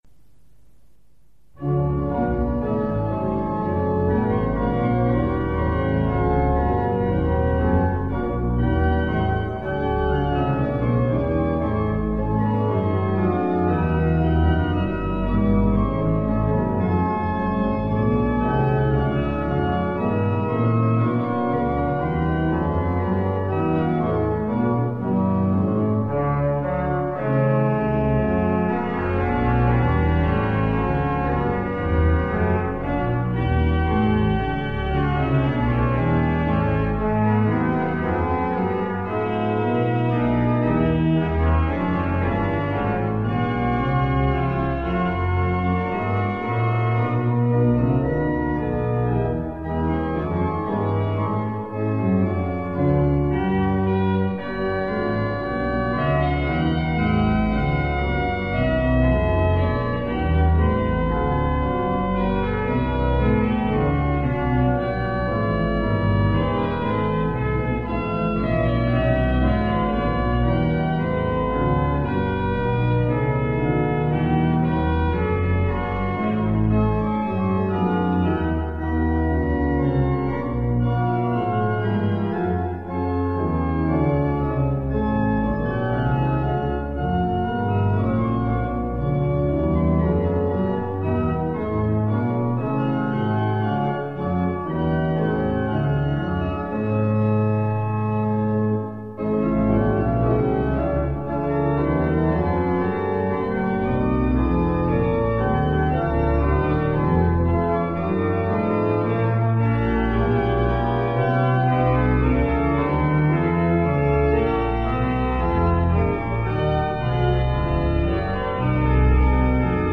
All other tracks are at St Anns Manchester.